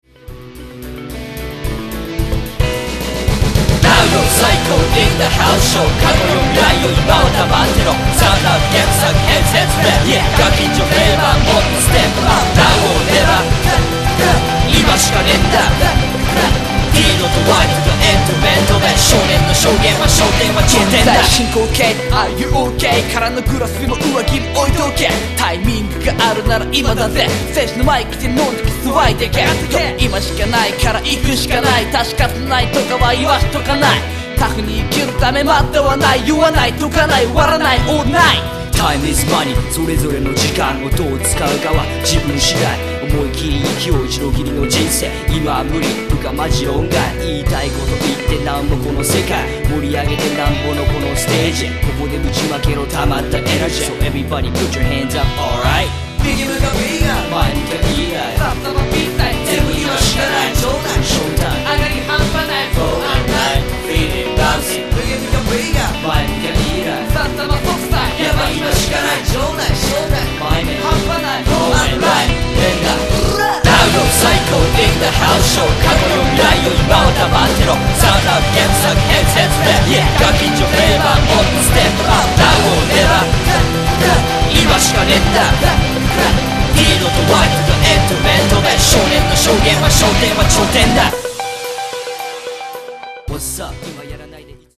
この曲はトラックだけを作りました。